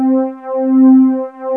ANALOGC4.wav